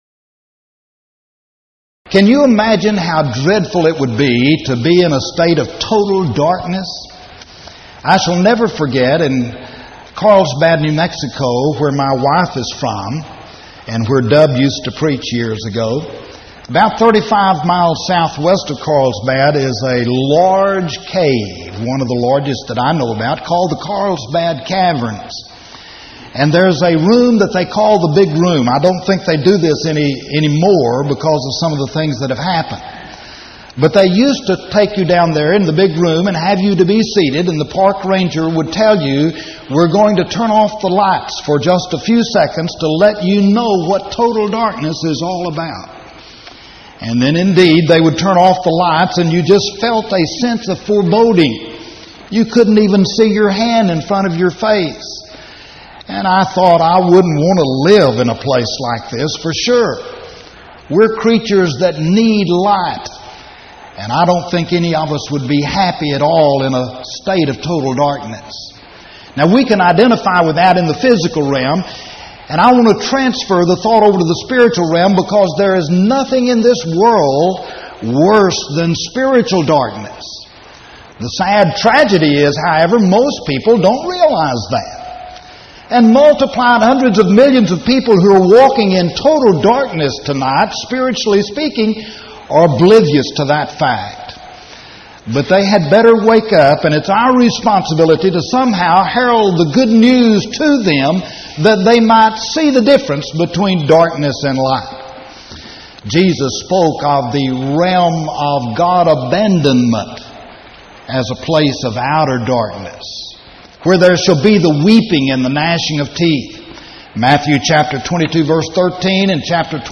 Series: Power Lectures Event: 1995 Power Lectures